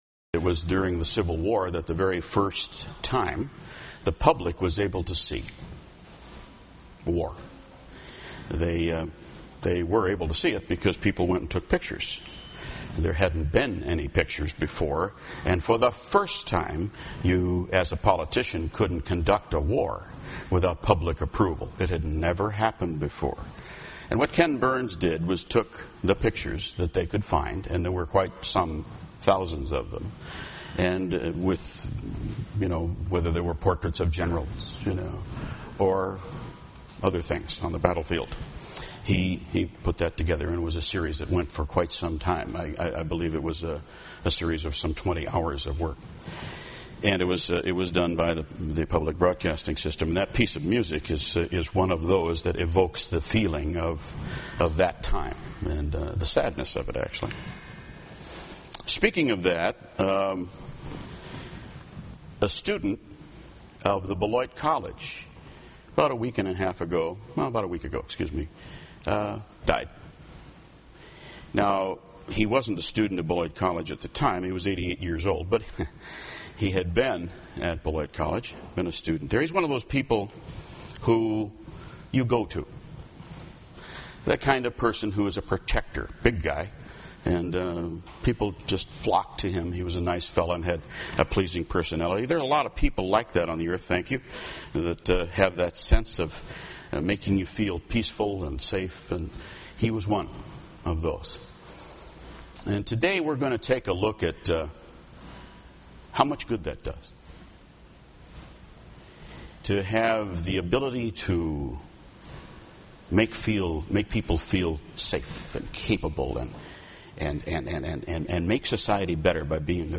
UCG Sermon Notes Intro: When we are civilized and manage society well, we are righteous.